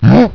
MOO-1.WAV